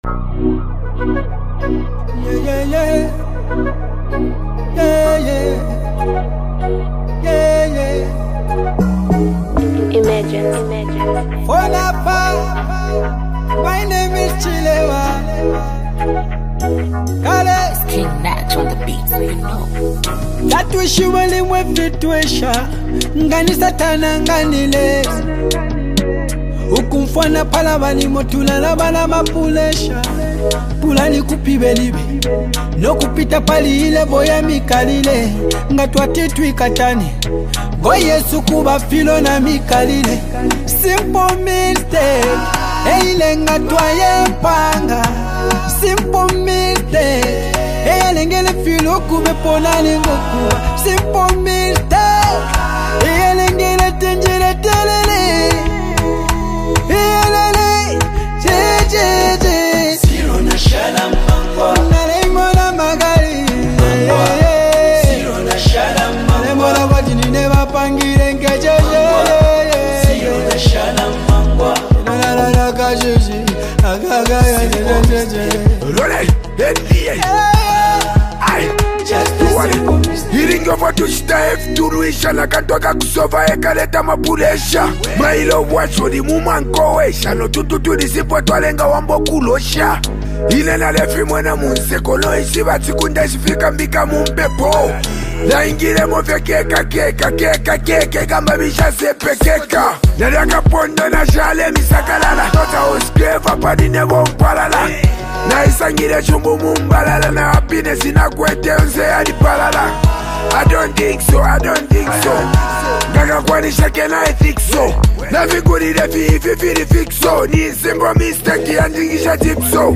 Zambia’s musical duo
energetic beats